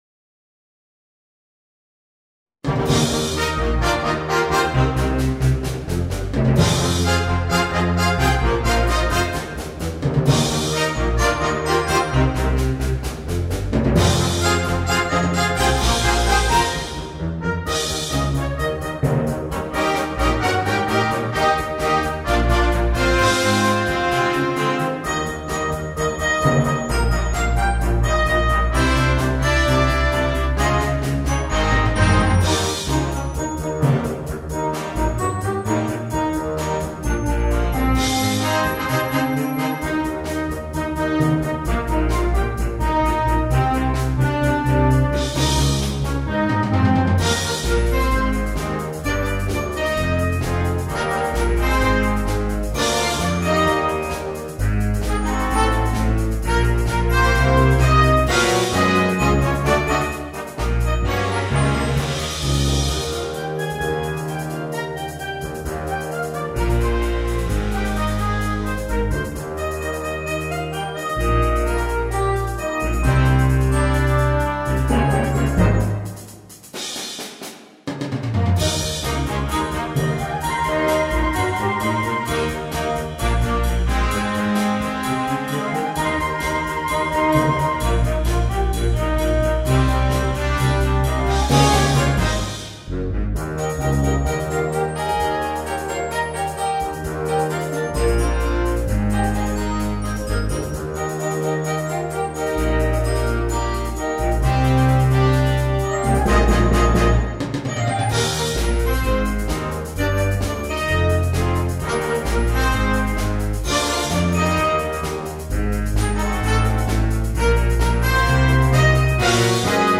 Un rock scatenato per banda.